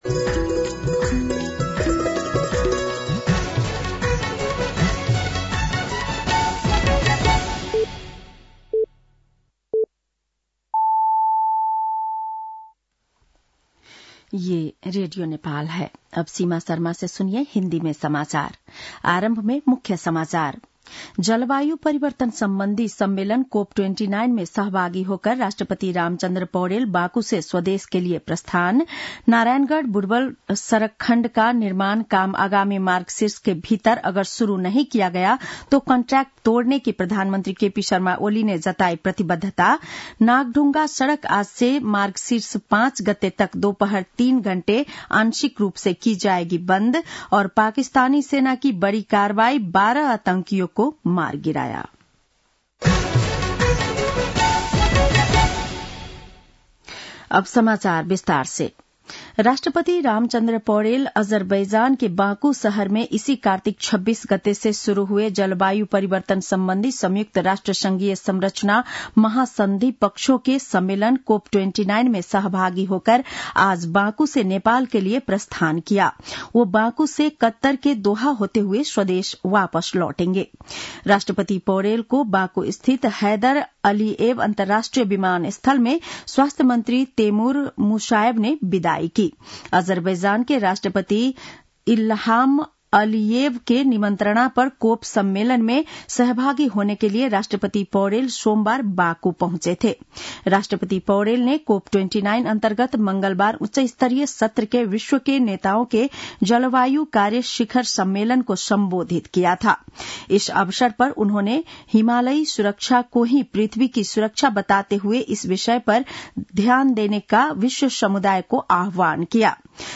बेलुकी १० बजेको हिन्दी समाचार : ३० कार्तिक , २०८१
10-PM-Hindi-NEWS-7-29.mp3